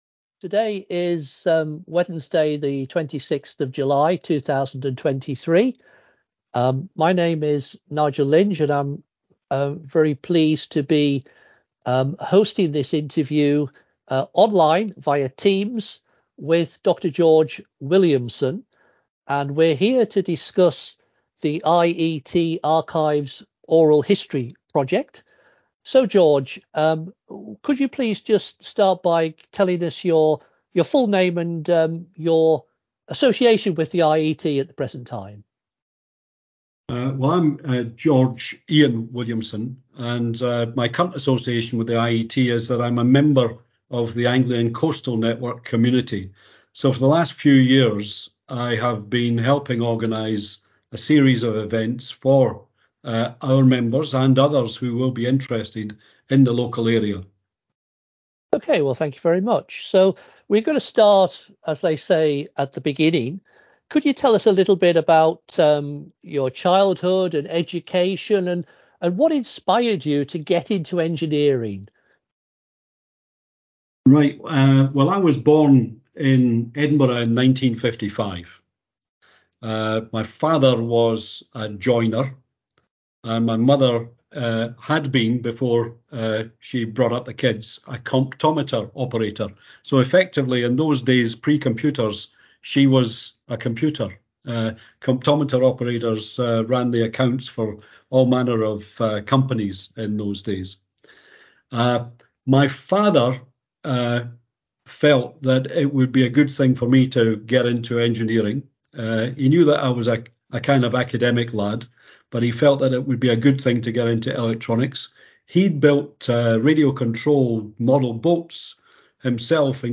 via Teams.